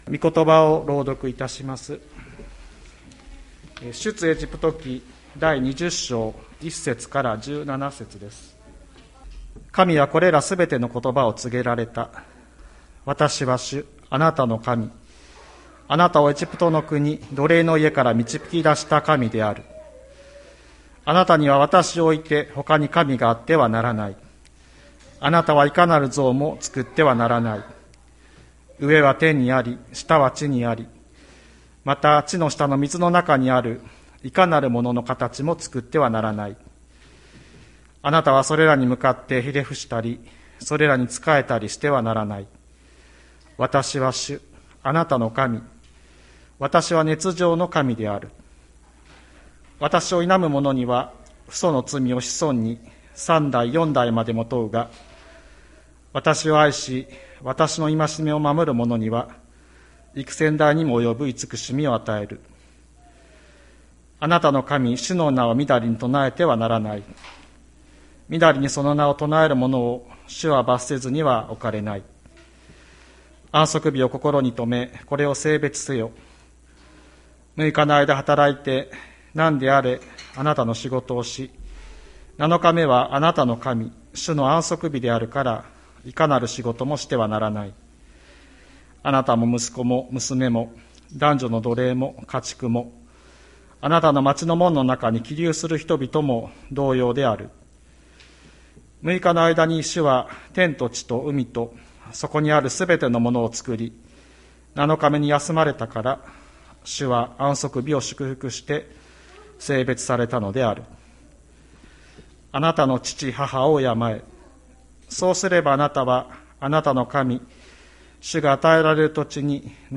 2022年03月27日朝の礼拝「健やかな性に生きる」吹田市千里山のキリスト教会